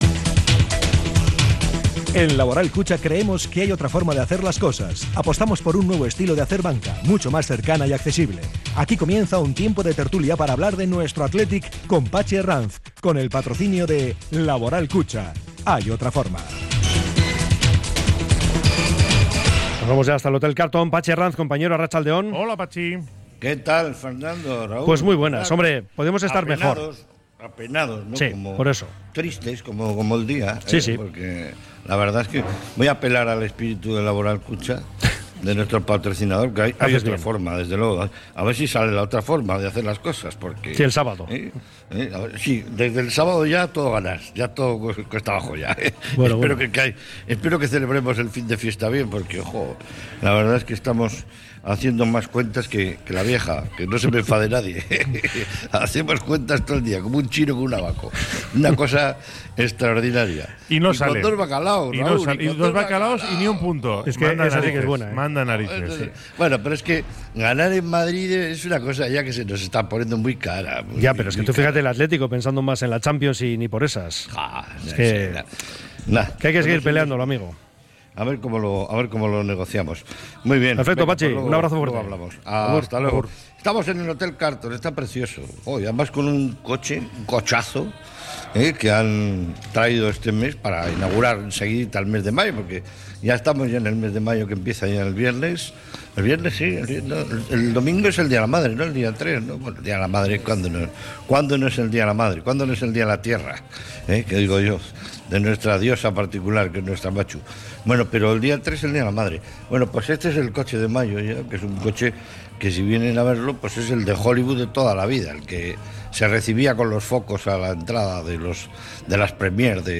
desde el Hotel Carlton